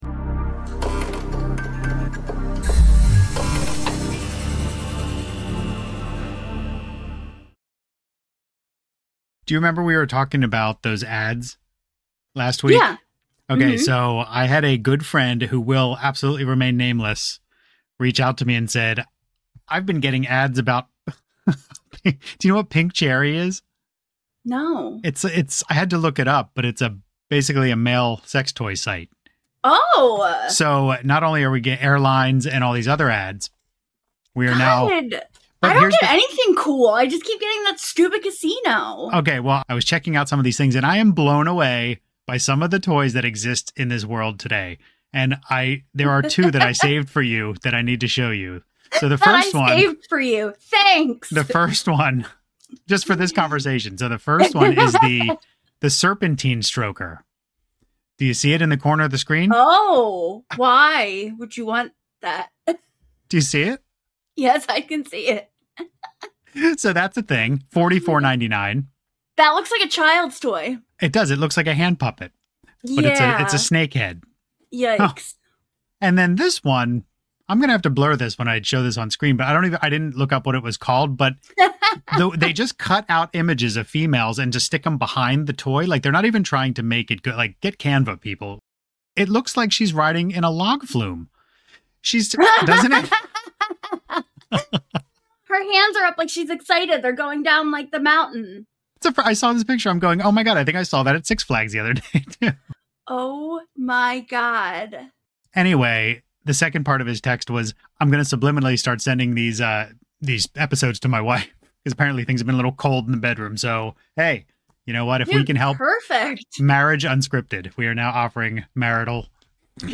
1 MBA2626 Extended Interview